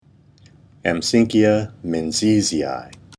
Pronunciation/Pronunciación:
Am-sínck-i-a  men-ziès-i-i